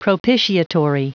Prononciation du mot propitiatory en anglais (fichier audio)
Prononciation du mot : propitiatory